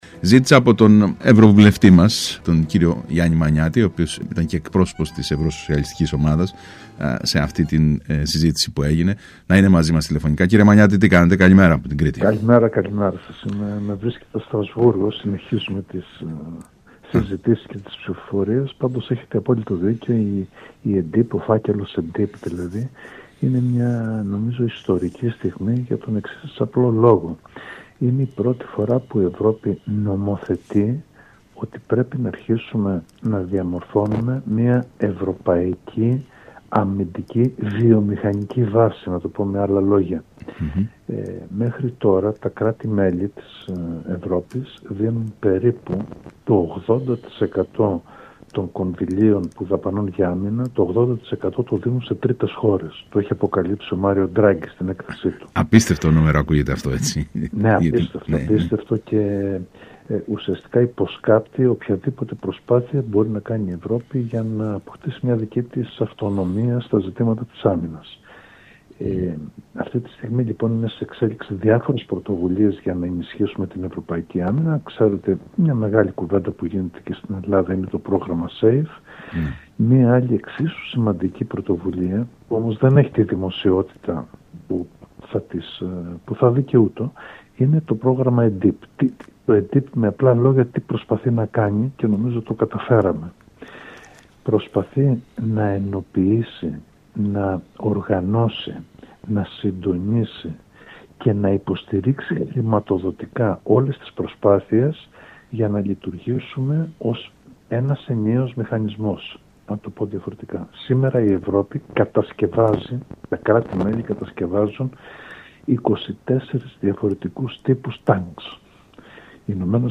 είπε ο κ. Μανιάτης μιλώντας στον ΣΚΑΪ Κρήτης 92,1